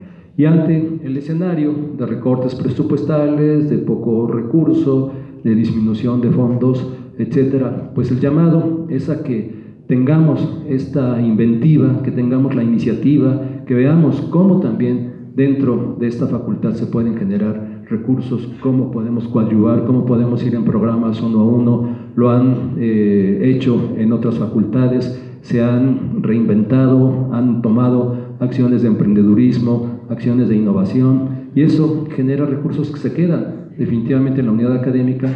Durante el Segundo Informe de Resultados